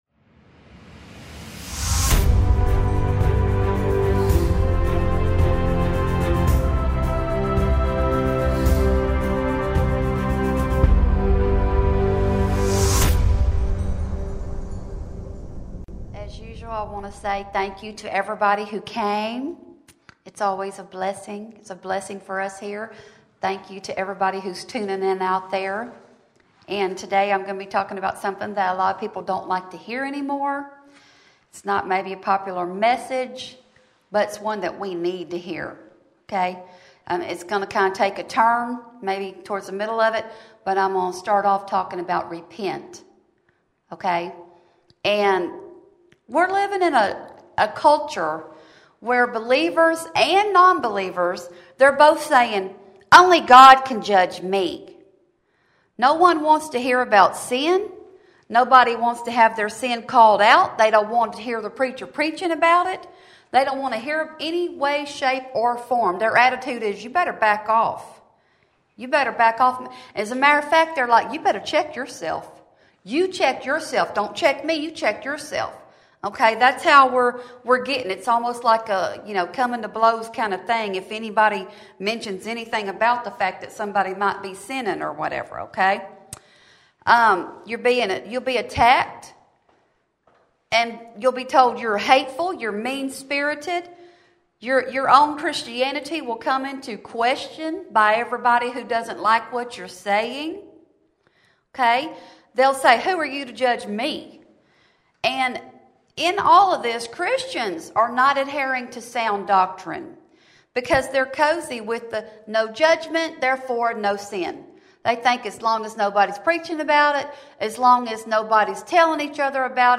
Welcome to the individual sermon page.